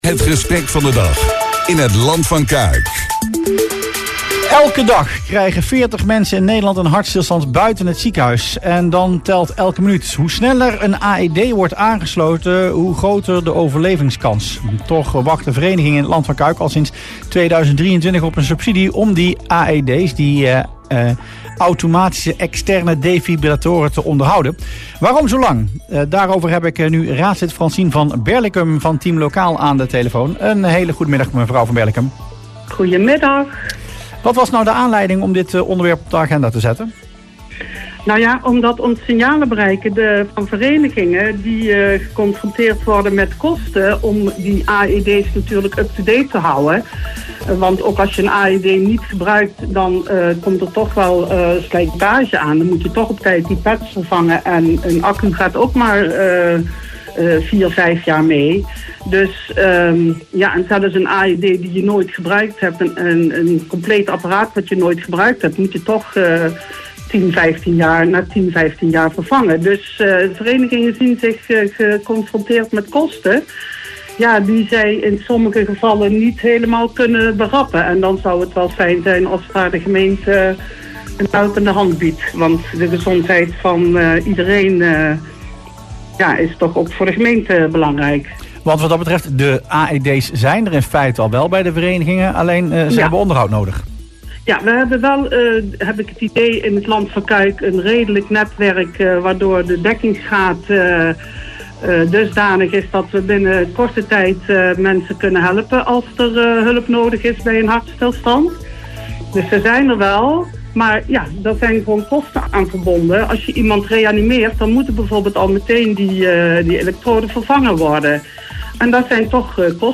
"AED’s moeten altijd update to date worden houden", aldus Francien van Berlicum, raadslid van Team Lokaal in het radioprogramma Rustplaats Lokkant op Omroep LvC.